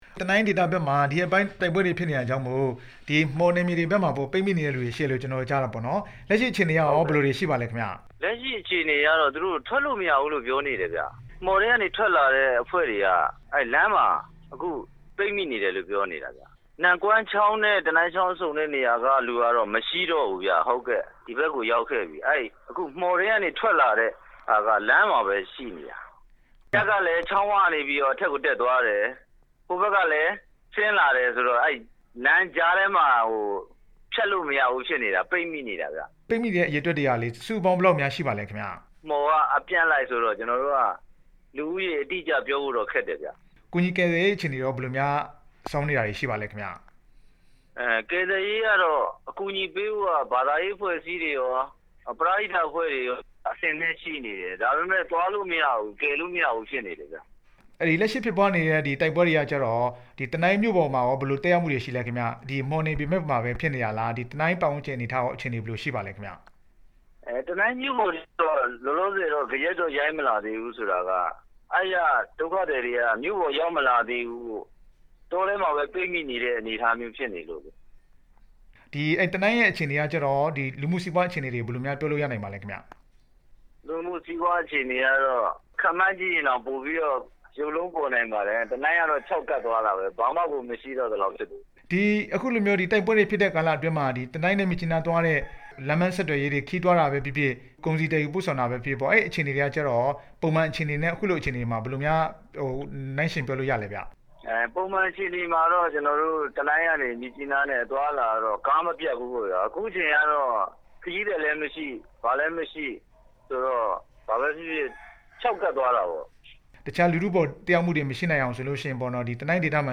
တနိုင်းဒေသ တိုက်ပွဲအခြေအနေ ဆက်သွယ်မေးမြန်းချက်